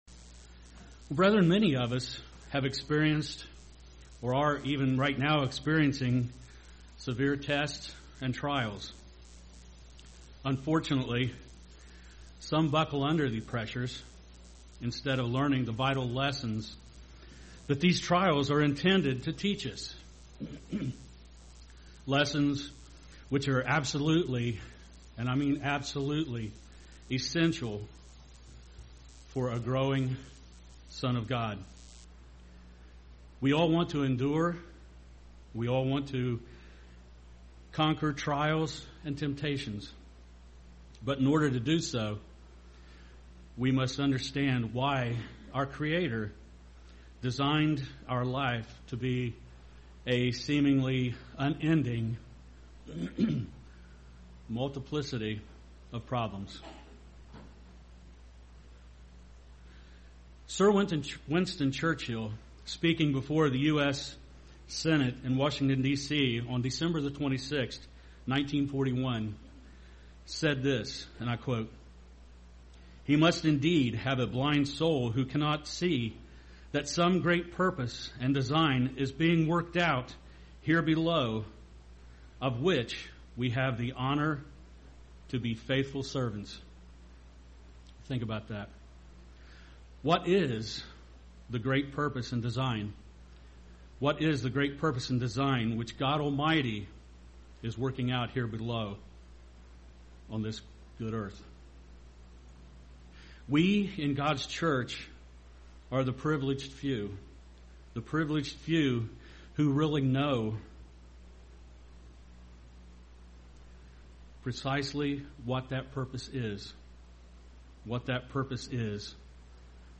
Sermons
Given in Portsmouth, OH